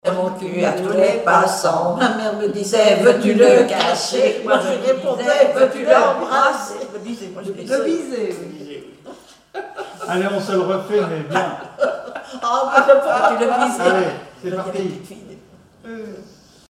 Localisation Île-d'Yeu (L')
formulette enfantine : amusette
comptines et formulettes enfantines
Pièce musicale inédite